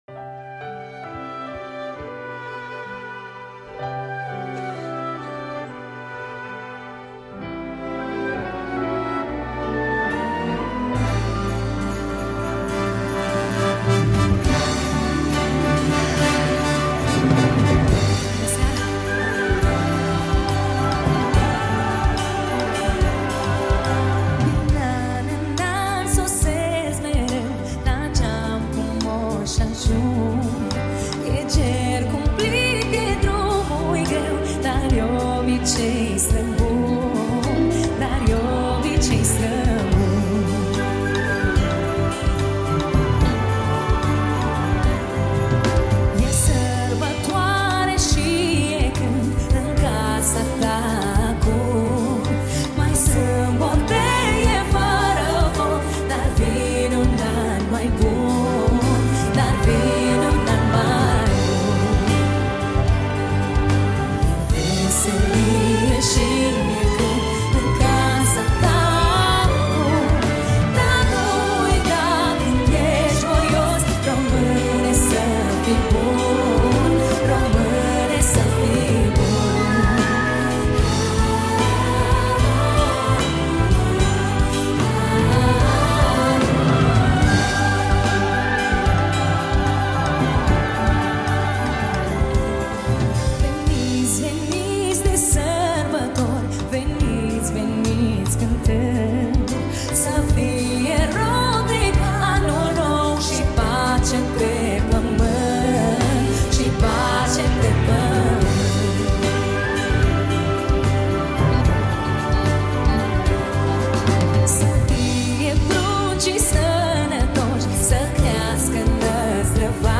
live La Opera Nationala
Data: 12.10.2024  Colinde Craciun Hits: 0